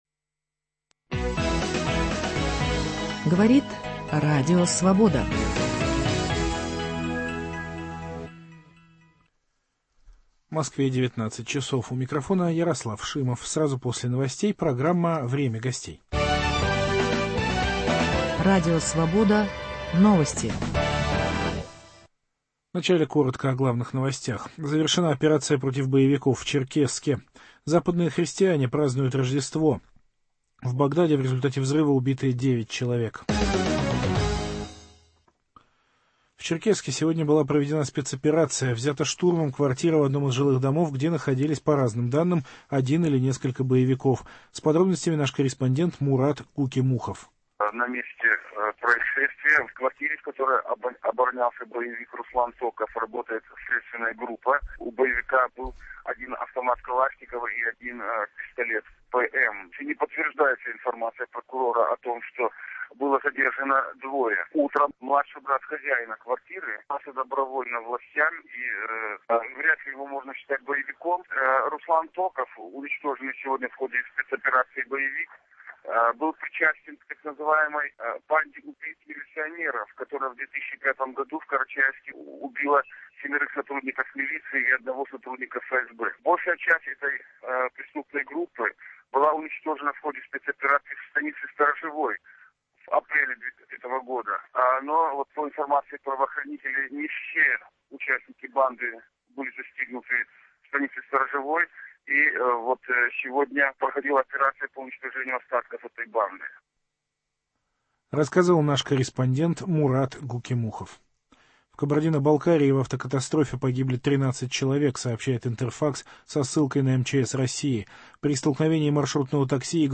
В программе выступит председатель Совета Федерации России, лидер партии "Справедливая Россия" Сергей Миронов.